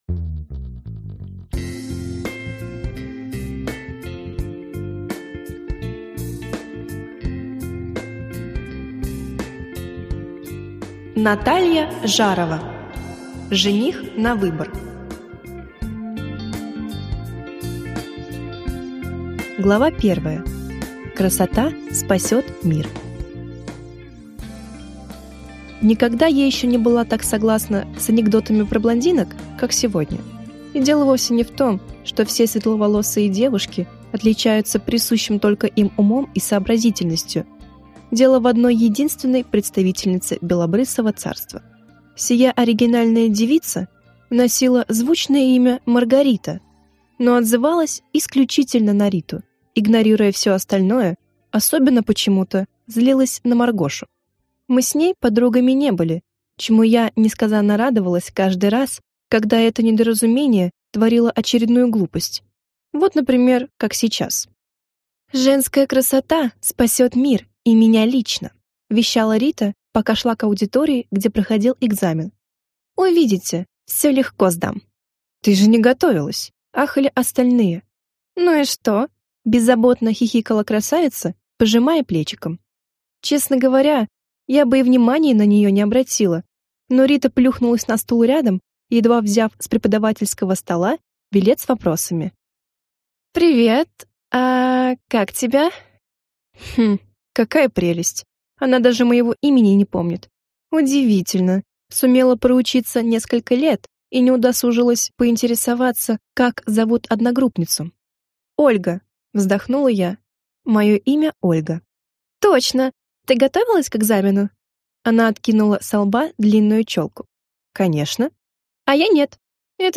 Аудиокнига Жених на выбор | Библиотека аудиокниг